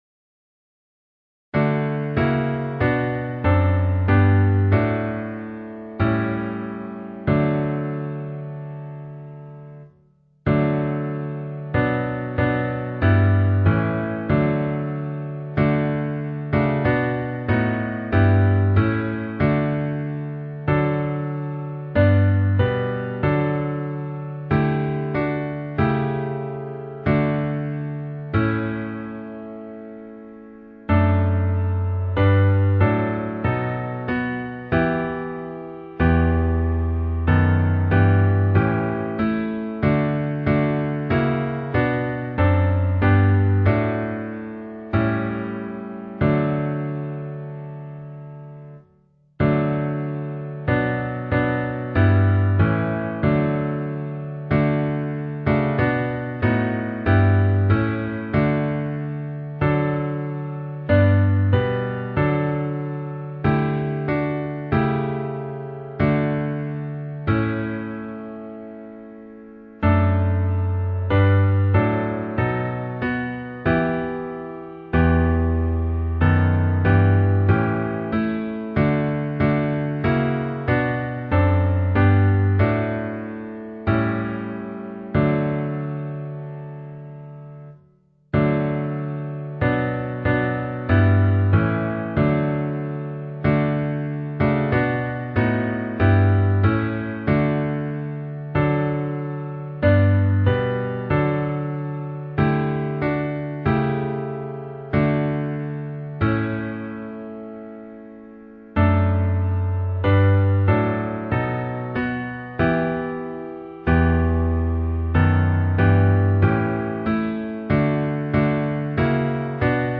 • Key: F
• Meter: 11.8.10.8